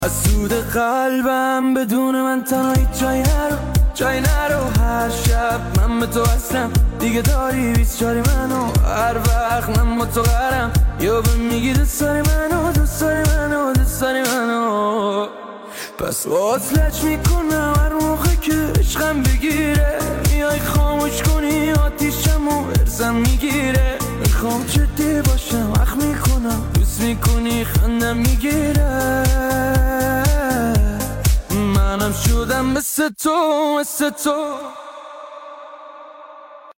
اهنگ شاد ایرانی